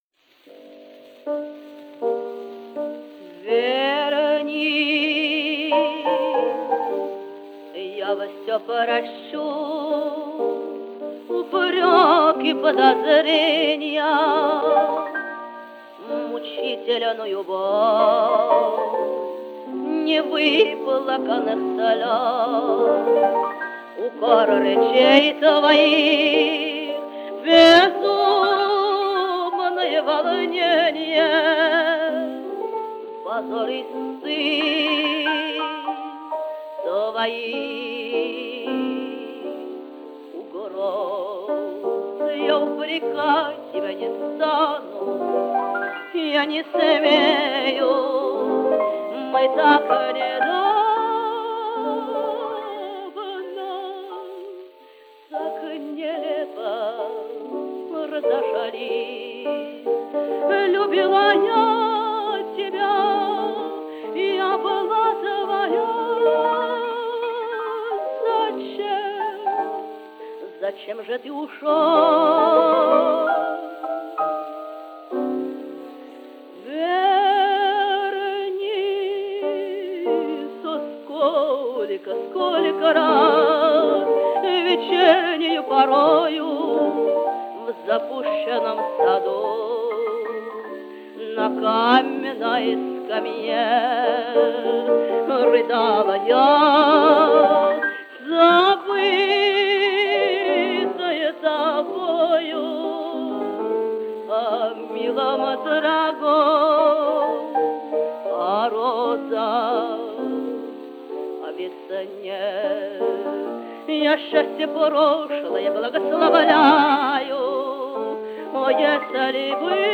Жанр: Романсы